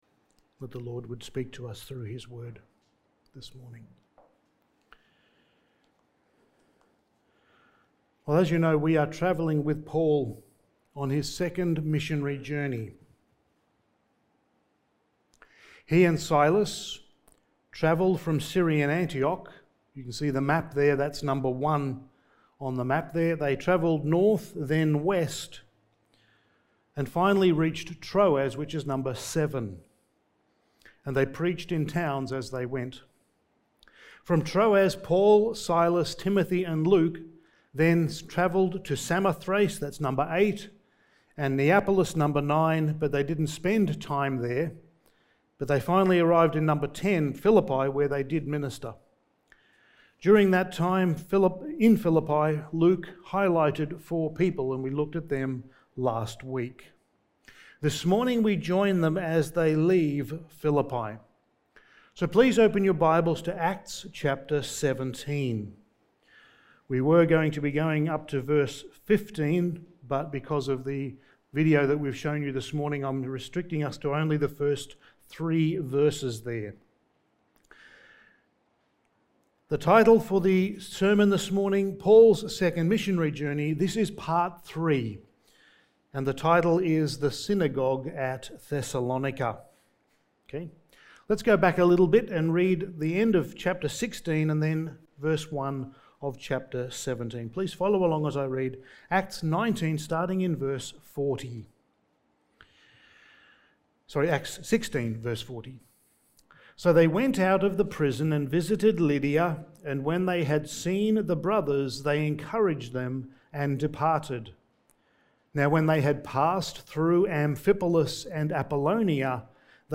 Passage: Acts 17:1-15 Service Type: Sunday Morning